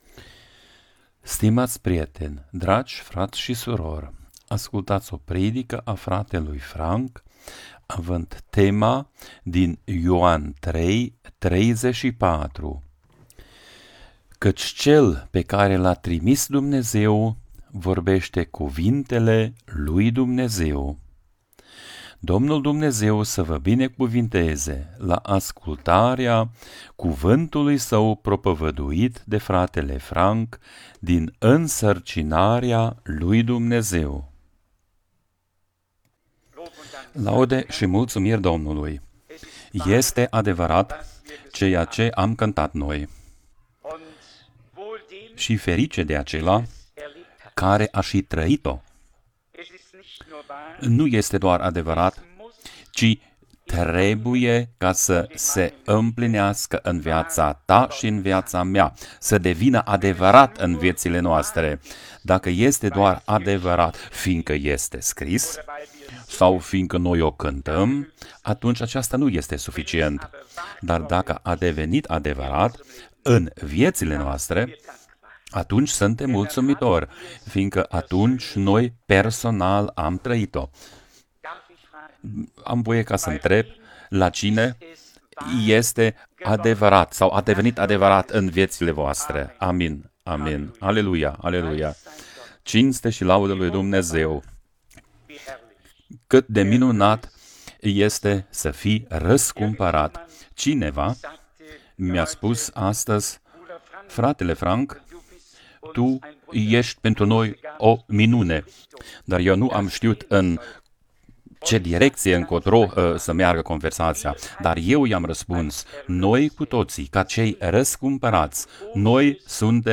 Redifuzata duminica 29 decembrie 2024